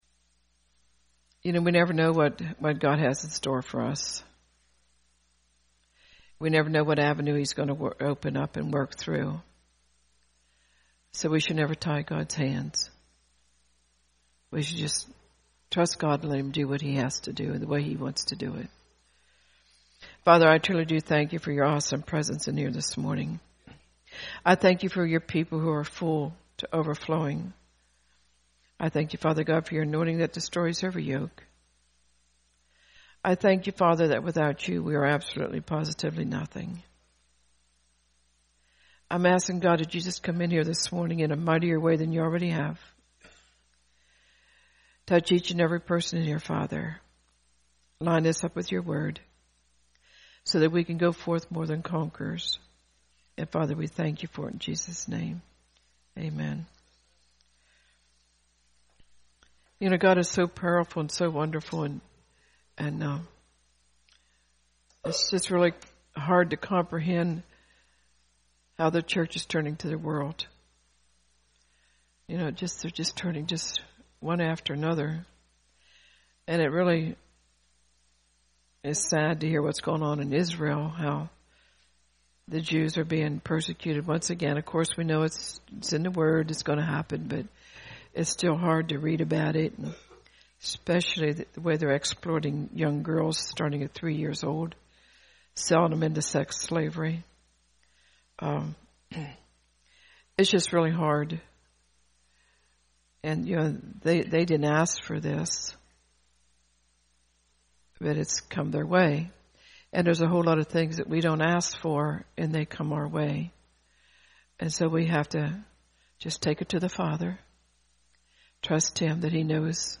Posted in Sermons